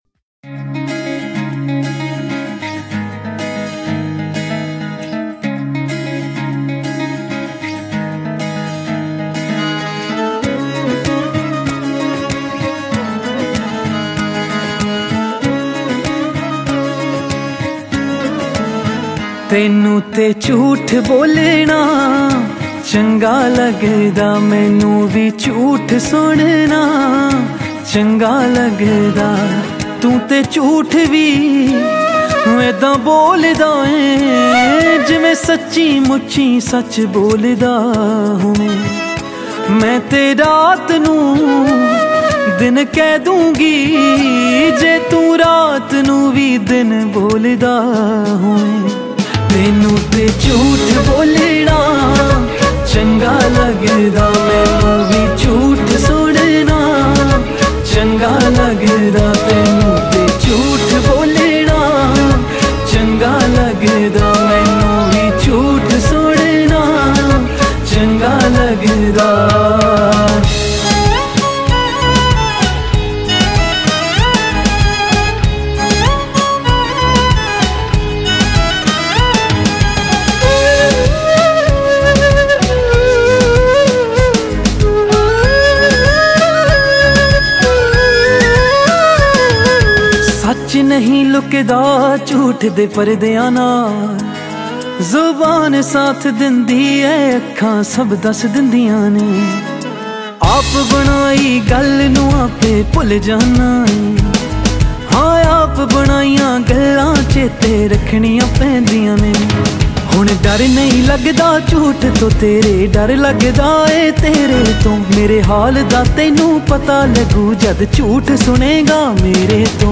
Genre Latest Punjabi Songs